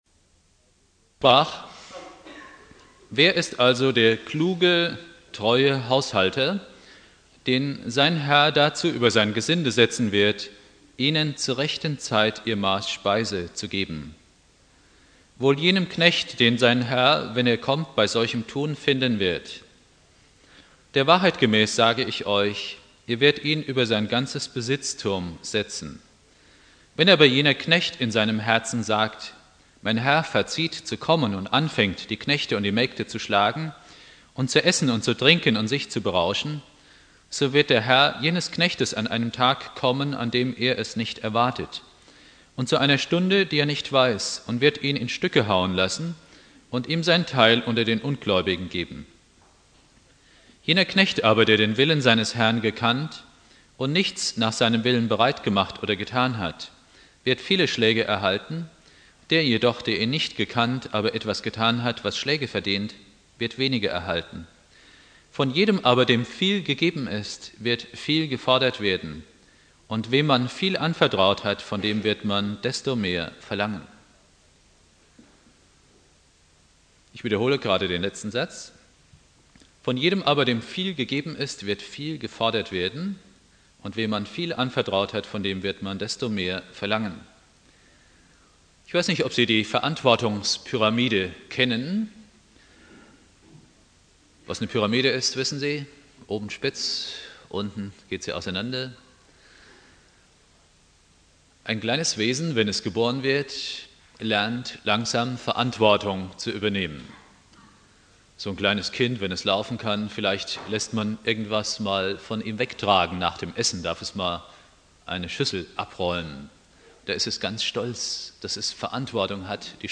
Predigt
Ewigkeitssonntag